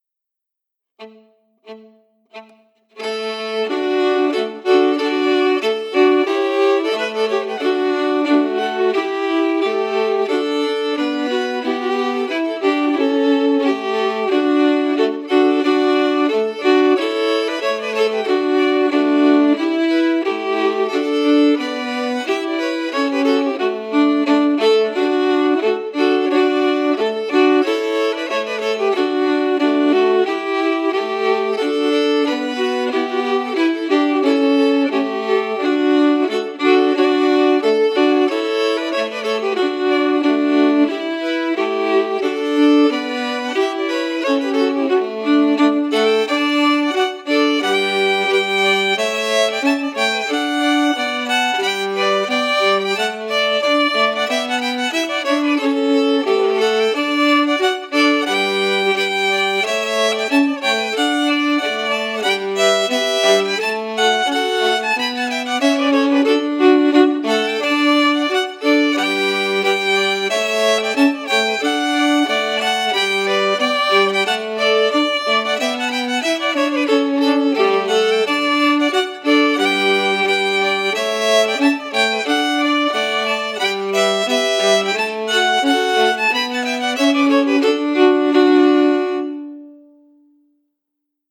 Key: D
Form: March
Harmony emphasis
Region: Shetland